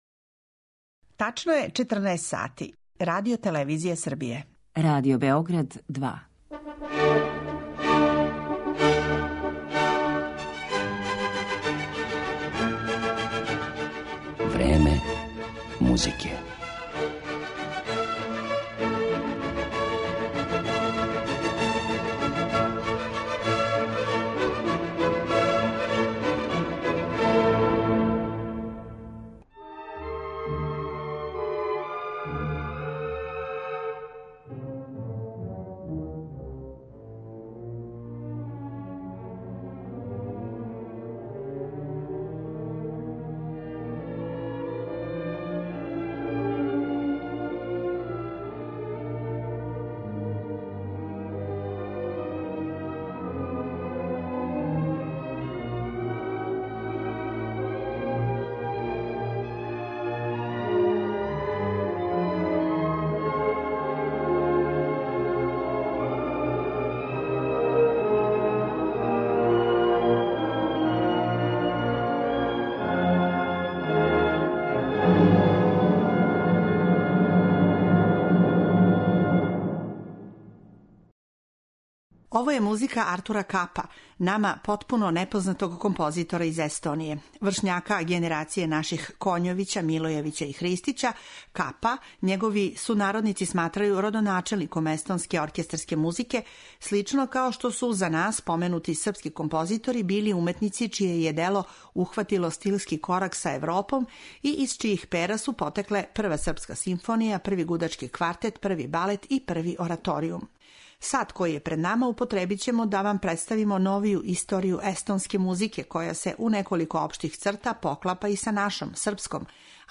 Уз причу о њеном развоју, слушаћете и дела аутора разних генерација: Артура и Вилхелма Капа, Хеина Елера, Едварда Тубина, као и свакако највећег композитора Естоније ‒ Арва Перта.